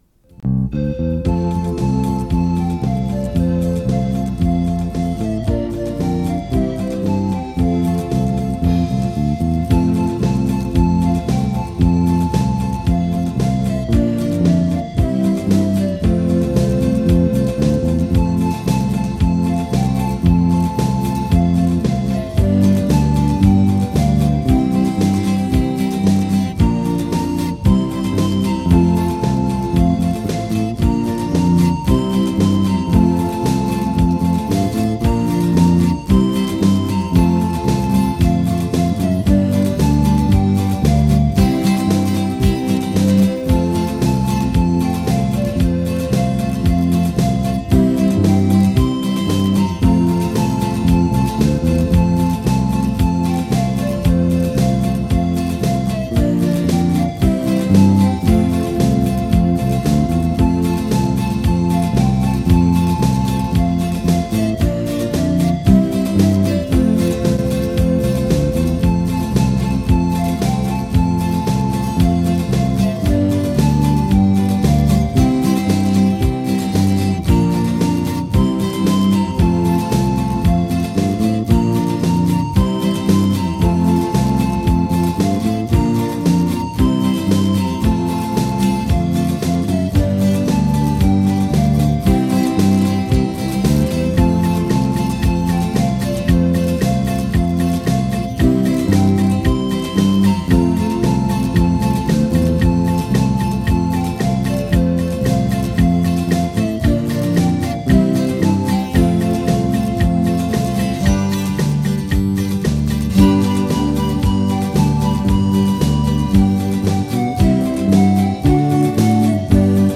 von Instrumental 1992
Lied-der-Hubertus-Schuetzengilde-1992-Instrumental.mp3